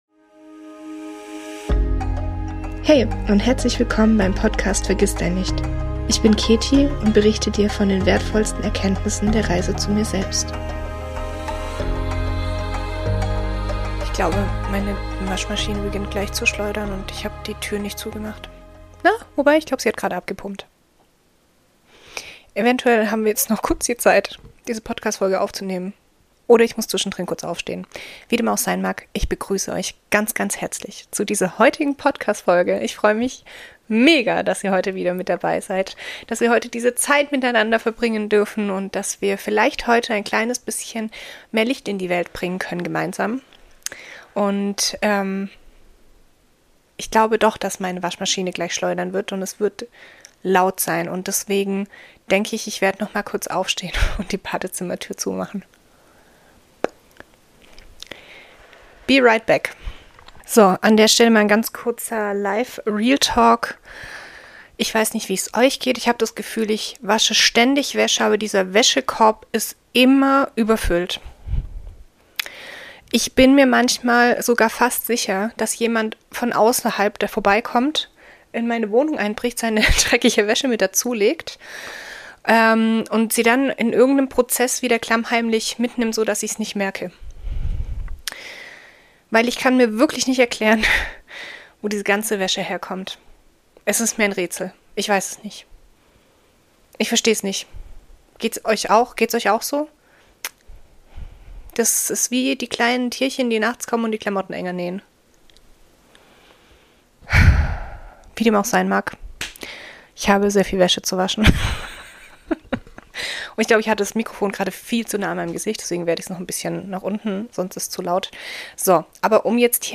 Mir liegt es sehr am Herzen, dir zu helfen, wieder ein Bewusstsein für dich und deine Bedürfnisse zu schaffen. Deshalb wird es in dieser Folge das erst mal auch eine Mini-Mini-Meditation geben. Ein Check-in, um wieder in Verbindung mit dir zu kommen.